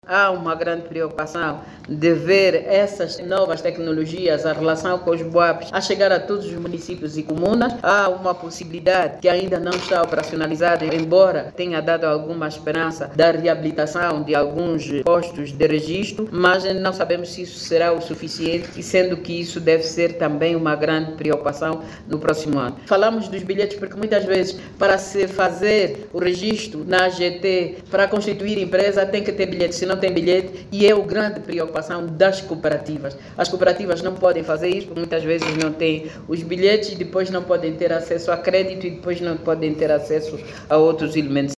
Para inverter essa realidade, a vice-governadora para o Sector Político, Social e Económico, Maria João Chipalavela, garante que o governo vai aumentar os postos de emissão do bilhete de identidade em todas as comunas.